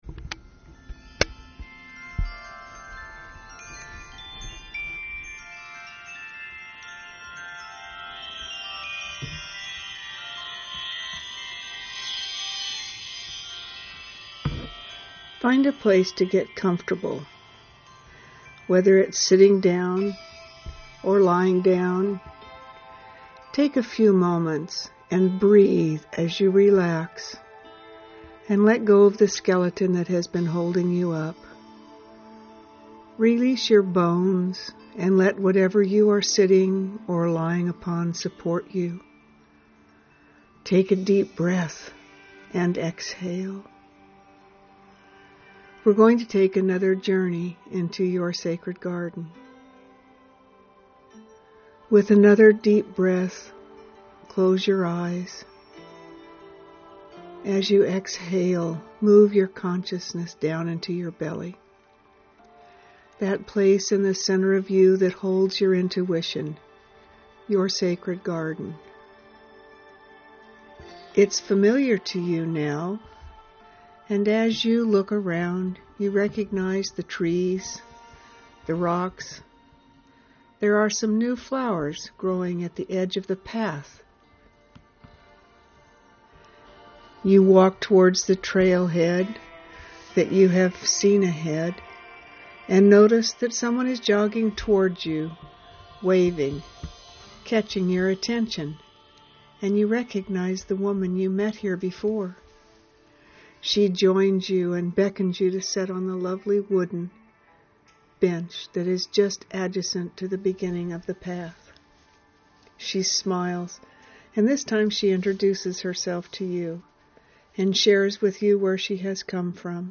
meditation-2-Letting-go-to-step-onto-the-path-ahead.mp3